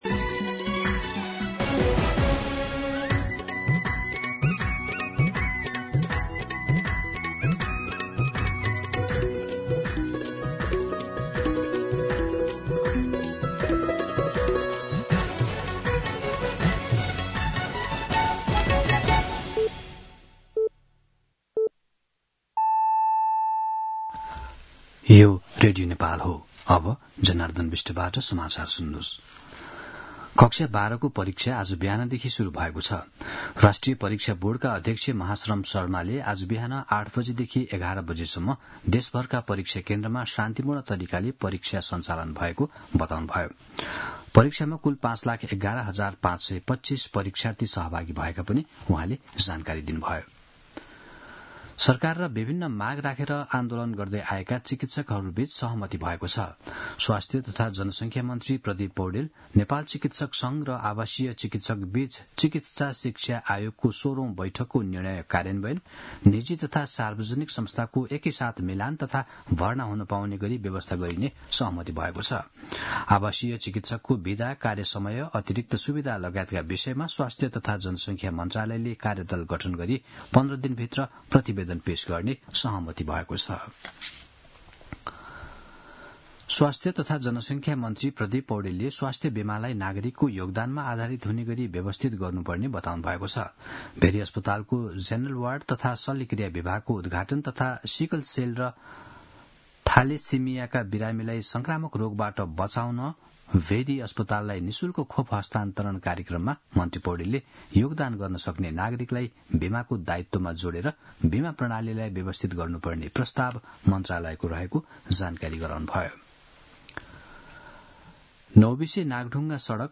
दिउँसो १ बजेको नेपाली समाचार : २१ वैशाख , २०८२
1-pm-Nepali-News.mp3